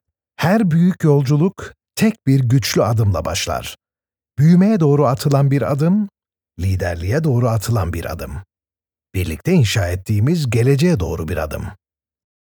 Male
Adult (30-50)
Corporate